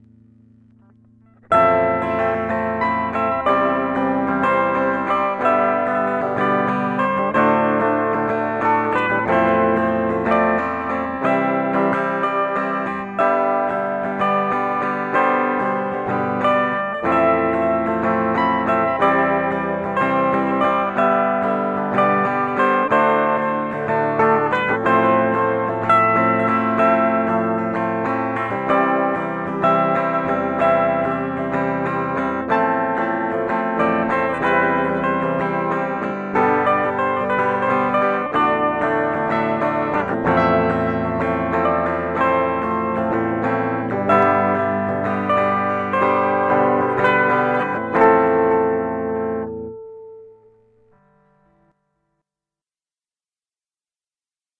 UA-1Gで録音
ギターつないで録音テスト。